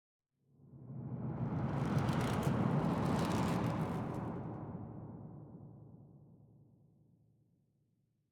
Minecraft Version Minecraft Version snapshot Latest Release | Latest Snapshot snapshot / assets / minecraft / sounds / ambient / nether / crimson_forest / addition2.ogg Compare With Compare With Latest Release | Latest Snapshot